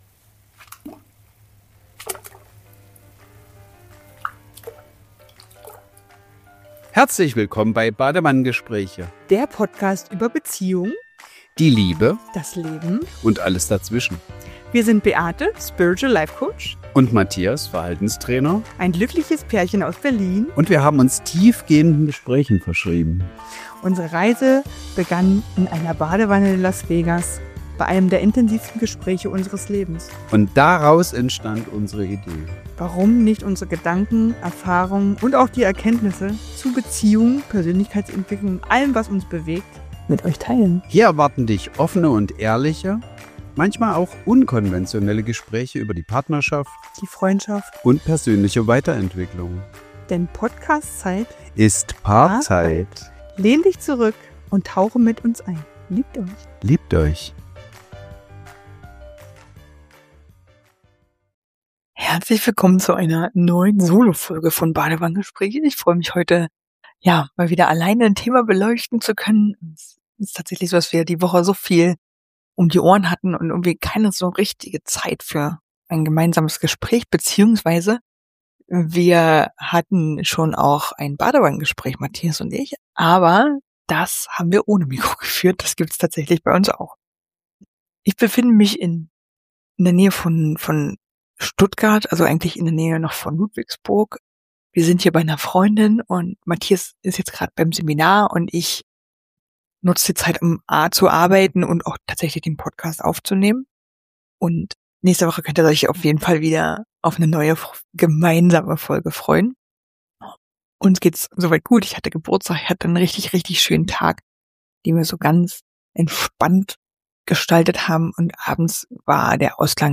In dieser Solo-Folge von Badewannengespräche spreche ich über den leisen Druck, immer besser, bewusster und „weiter“ werden zu müssen.